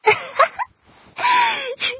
Laugh3 - 副本.mp3